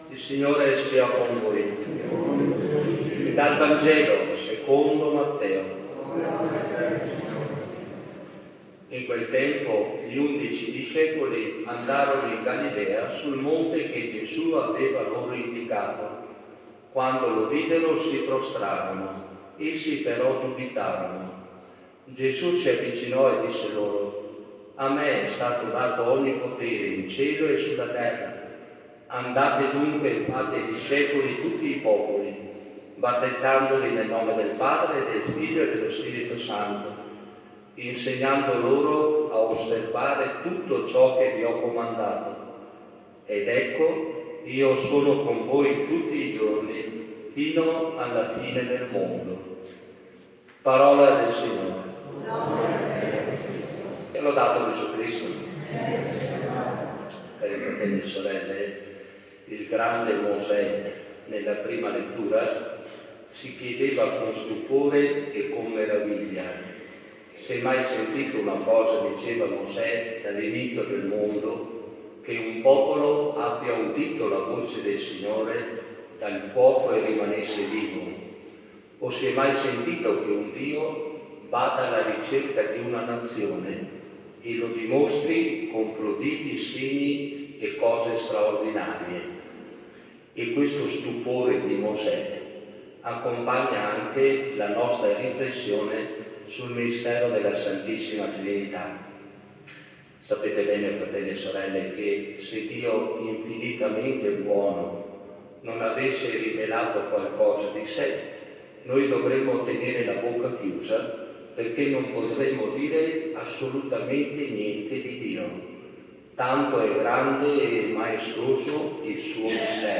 SFM-omelia-2024.mp3